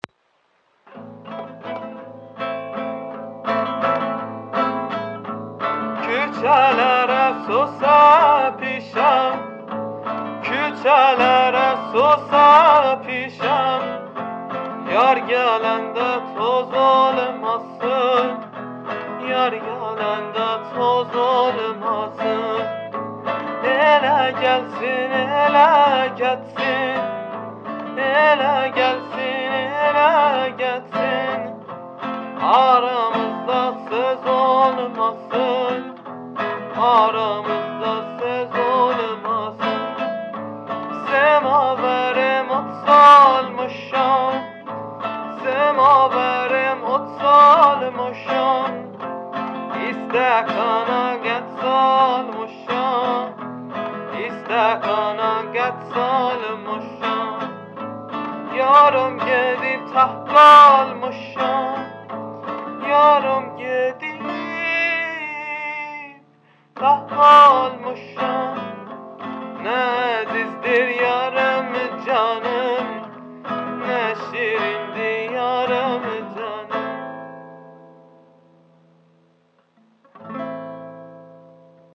تكنوازی